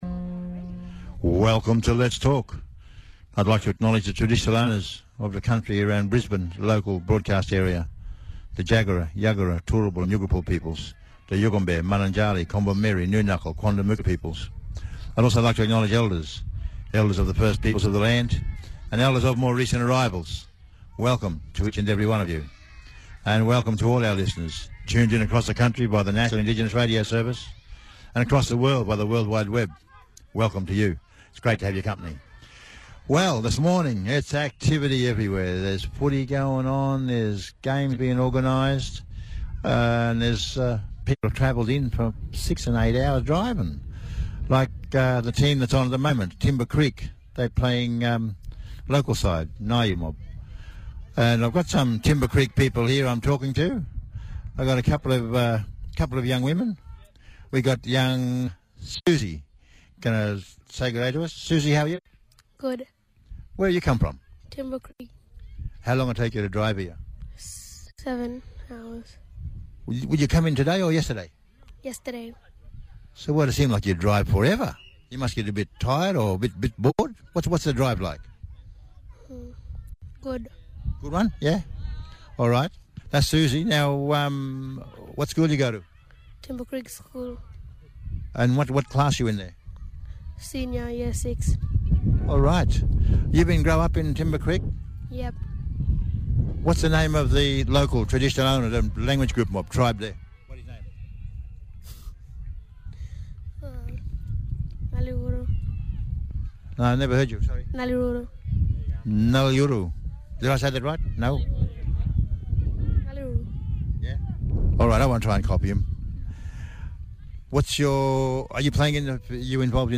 (pronounced Nai-you). Nauiyu is 230km south of Darwin, The Traditional Owners of the area are the Malak Malak people.